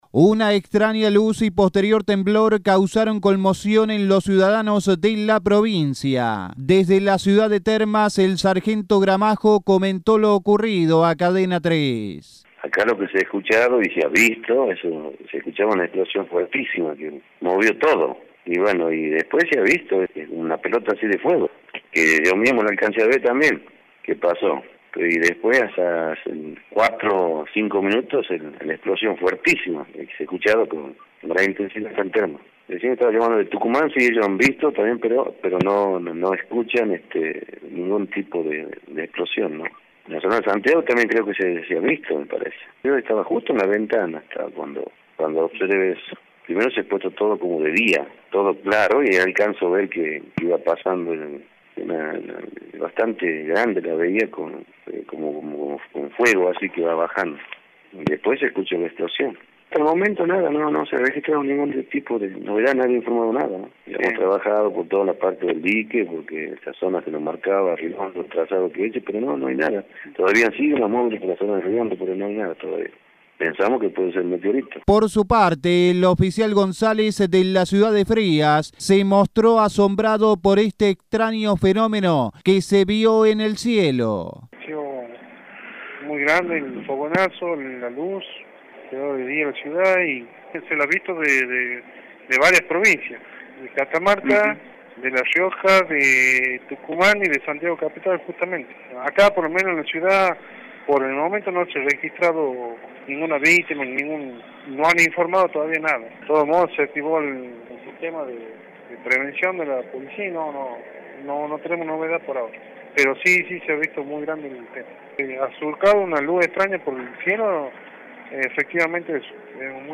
Informe del periodista de Cadena 3